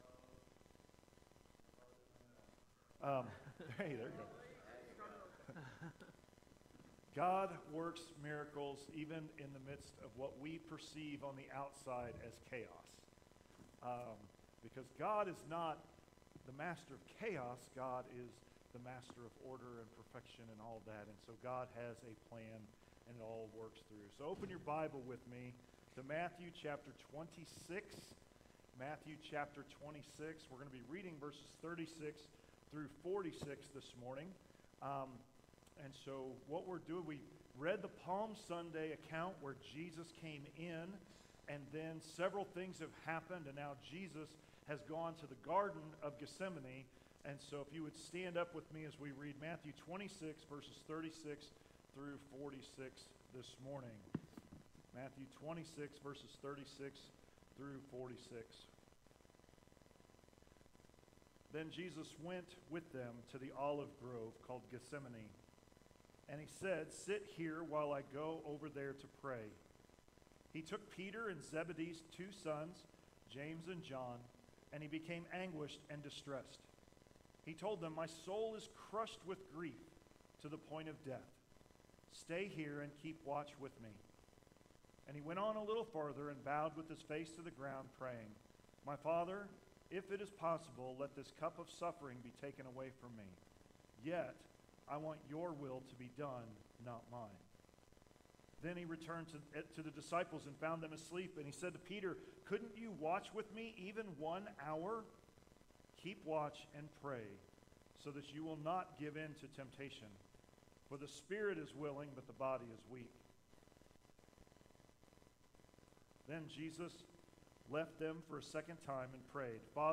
Bible Study: Surrendering to God – A Holy Week Reflection